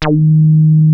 WEST SOFT#D3.wav